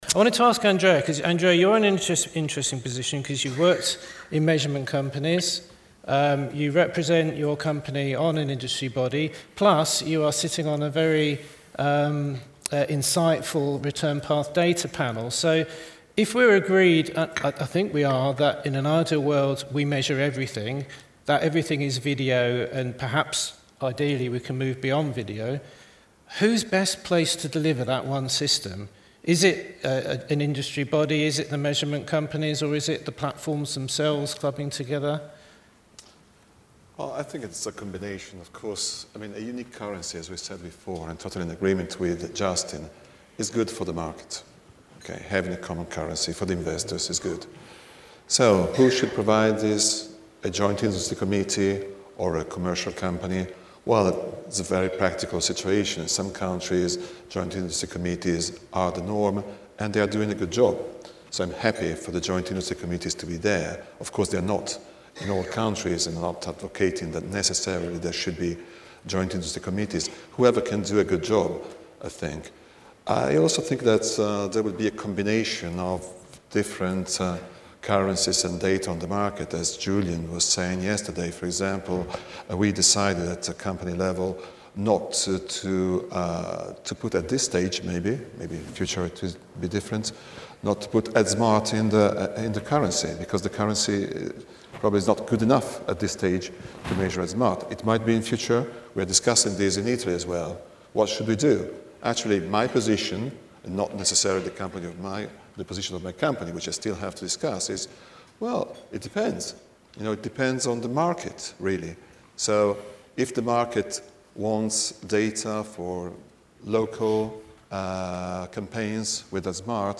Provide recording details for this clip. The audio of the final panel debate at the 2016 asi International Television & Video Conference on 2nd -4th November in Budapest, Hungary, can be heard in three parts.